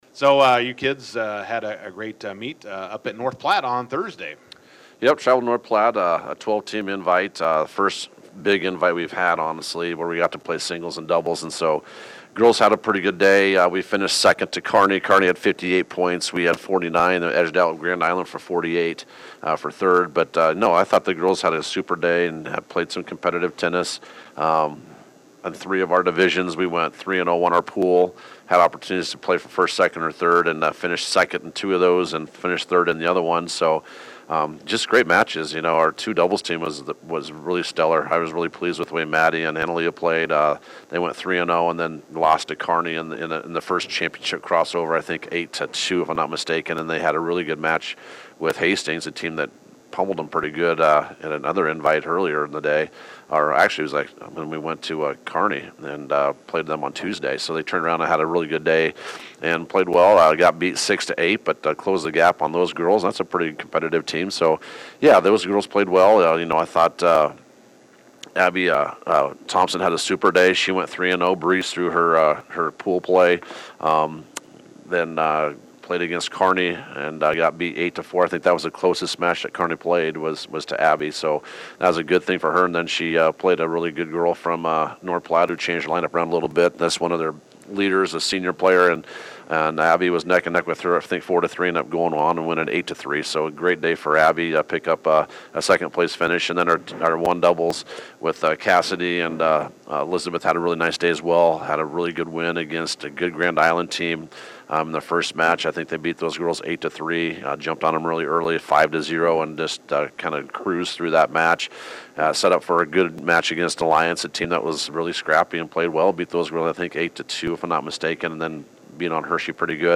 INTERVIEW: Bison girls tennis braces for a big week, starting today vs. Gothenburg.